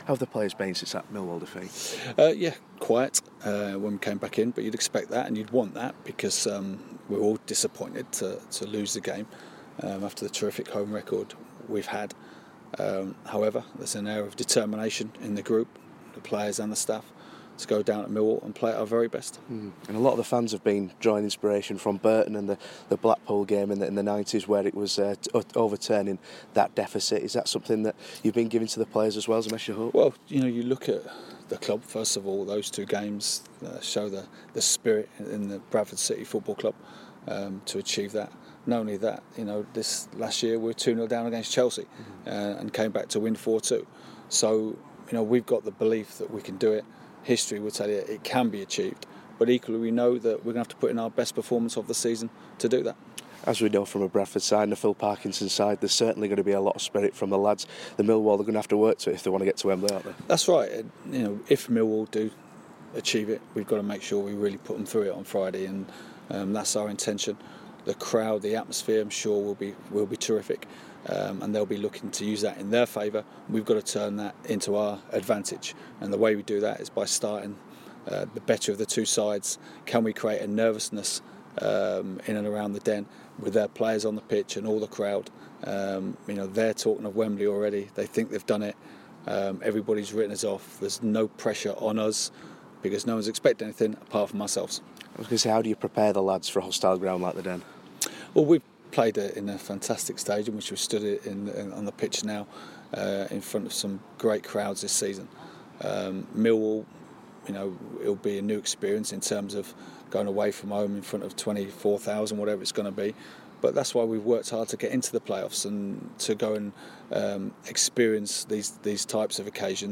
Bradford City Manager Phil Parkinson talks to Radio Yorkshire ahead of the play off semi final second leg at Millwall